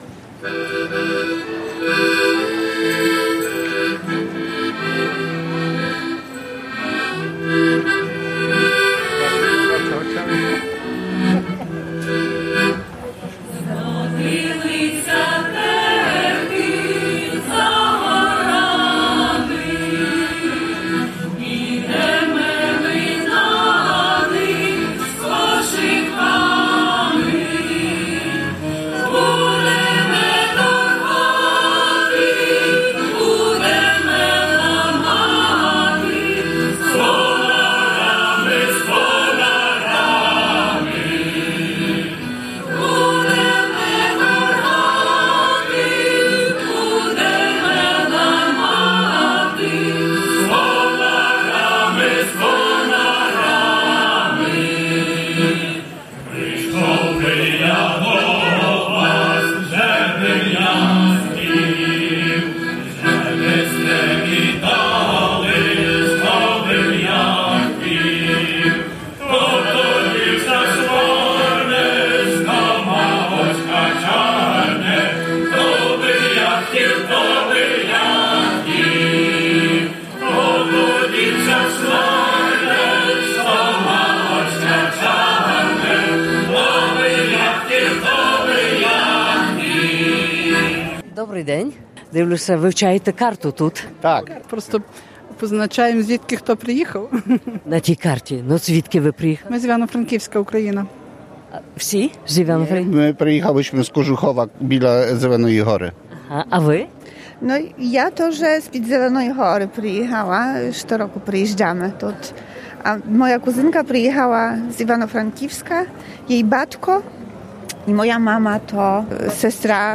В Ждині коло Горлиць проходила 42 Лемківська Вара. Звучали пісні, була молитва, роздуми та спогади. На своє свято приїхало кілька тисяч лемків з усього світу.